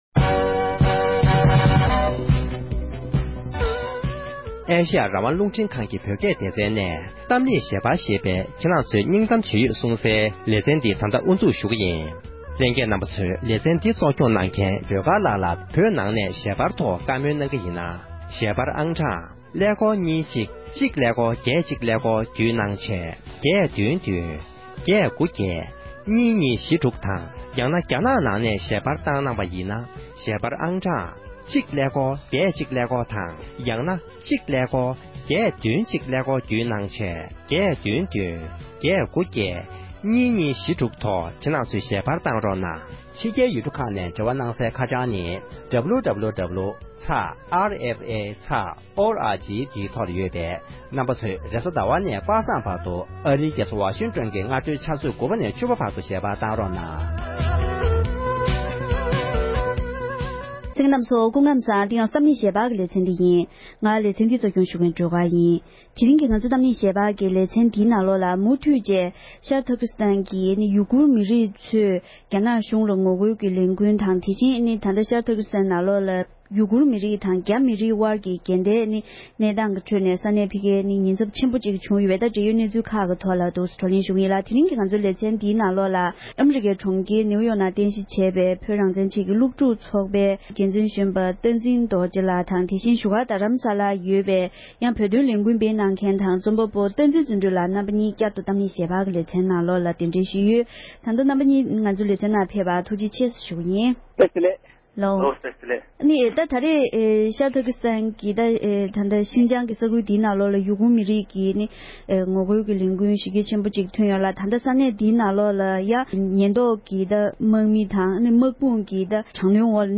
༄༅༎དེ་རིང་གི་གཏམ་གླེང་ཞལ་པར་གྱི་ལེ་ཚན་ནང་ཉེ་ཆར་ཤར་ཏུརྐིསྟན་ནང་ཐོན་པའི་རྒྱ་ནག་གཞུང་ལ་ངོ་རྒོལ་གྱི་ལས་འགུལ་དང་ན་ནིང་བོད་ནང་ཐོན་པའི་བོད་མིའི་ཞི་རྒོལ་གྱི་སྐོར་ལ་དབྱེ་ཞུས་པར་གསན་རོགས༎